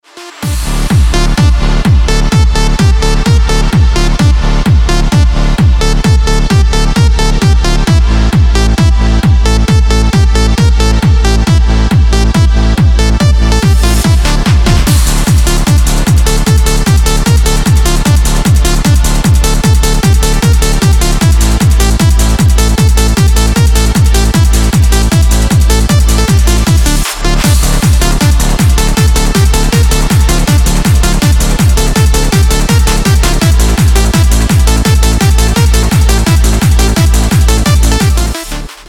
Ускоряющийся громкий рингтон
звучные рингтоны
Громкие рингтоны